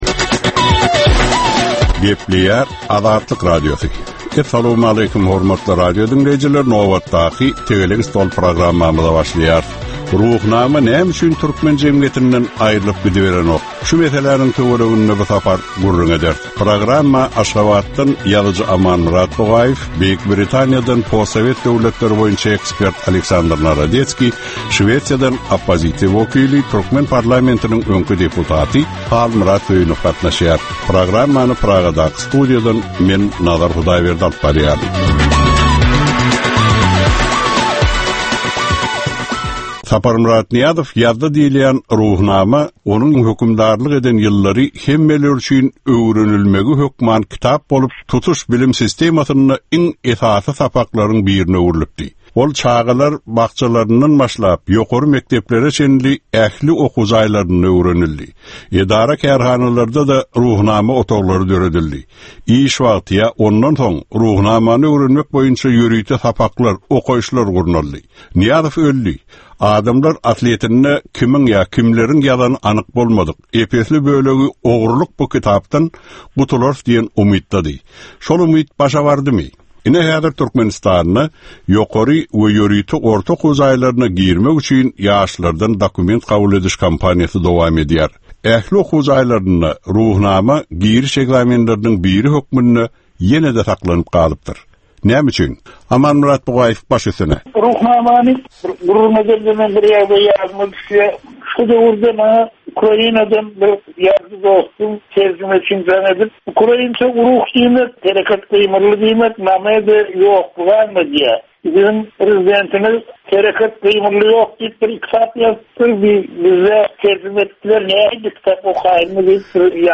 Jemgyýetçilik durmusynda bolan ýa-da bolup duran sonky möhum wakalara ýa-da problemalara bagyslanylyp taýyarlanylýan ýörite Tegelek stol diskussiýasy. 30 minutlyk bu gepleshikde syýasatçylar, analitikler we synçylar anyk meseleler boýunça öz garaýyslaryny we tekliplerini orta atýarlar.